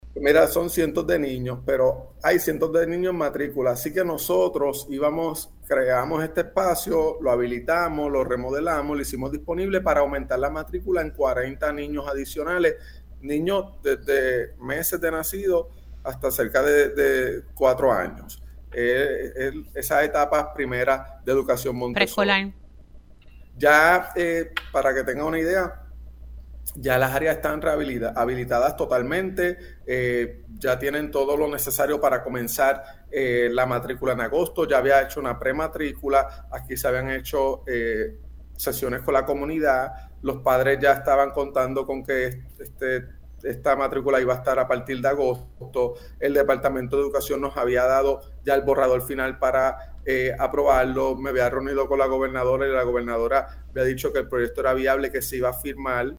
Habíamos llegado a un acuerdo de expandir ese programa en nuestro pueblo de Aguada“, explicó Cortés en Pega’os en la Mañana.